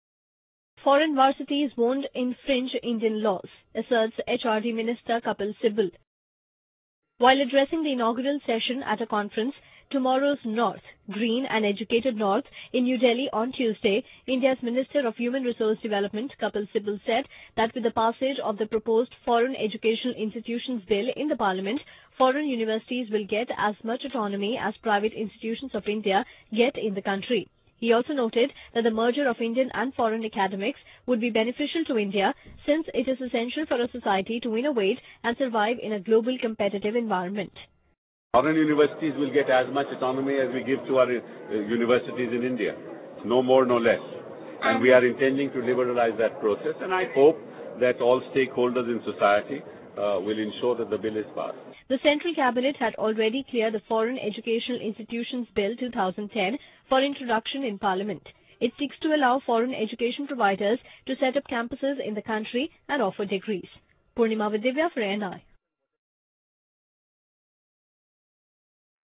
While addressing the inaugural session at a conference 'Tomorrow's North: Green and Educated North' in New Delhi on Tuesday India's Minister of Human Resource Development Kapil Sibal said that with the passage of the proposed Foreign Educational Institutions Bill in the parliament, foreign universities will get as much autonomy as private institutions of India get in the country.